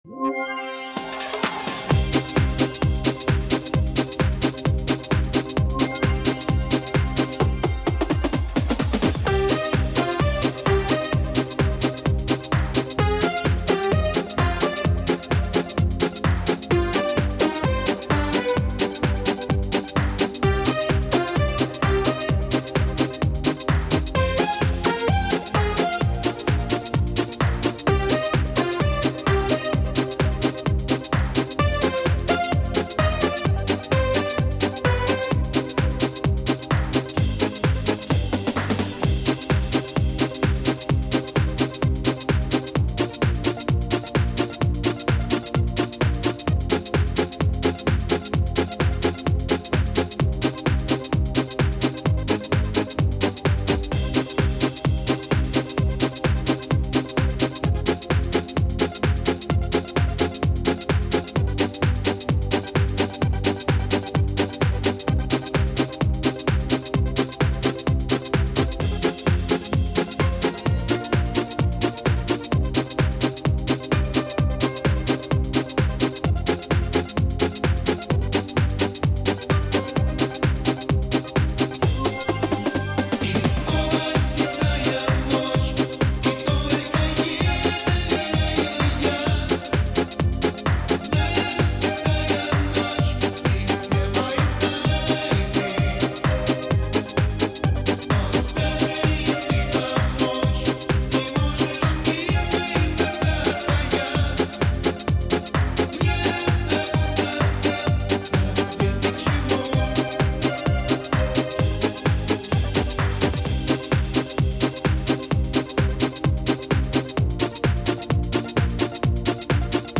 минусовка версия 43507